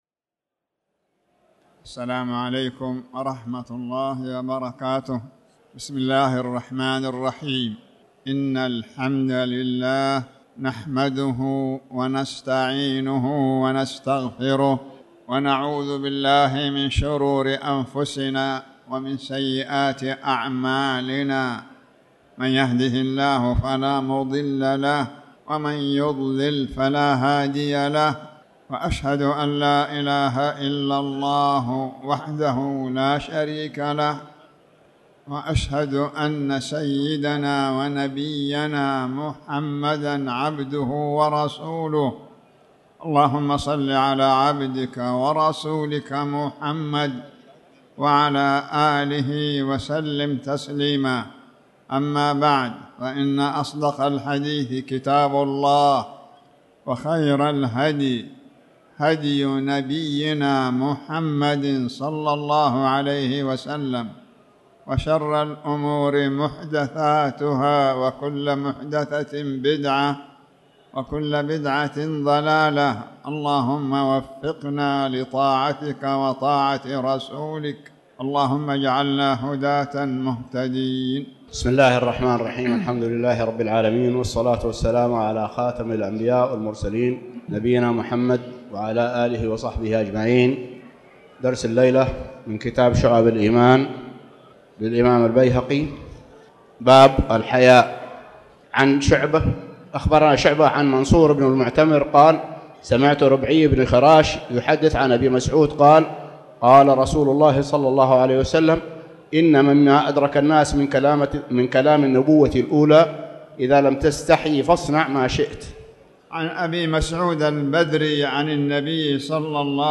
تاريخ النشر ٢٠ ربيع الثاني ١٤٣٩ هـ المكان: المسجد الحرام الشيخ